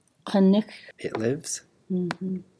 Lives /…nex/